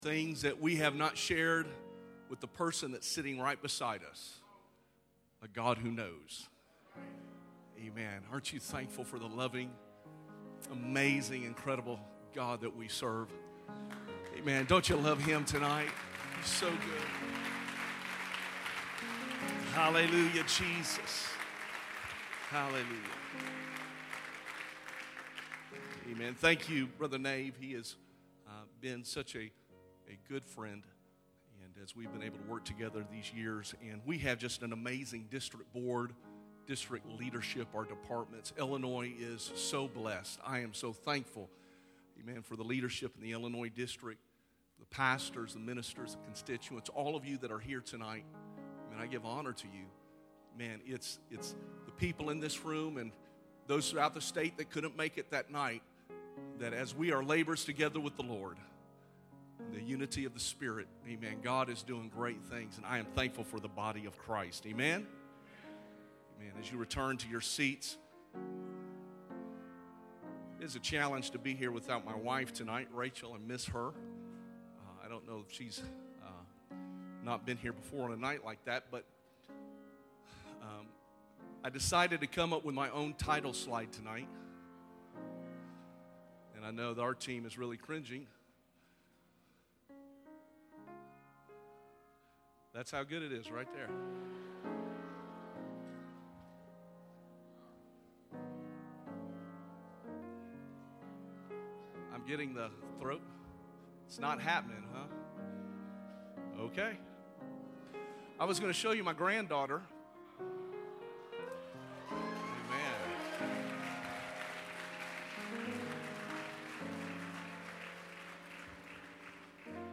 Sermon Archive | Illinois District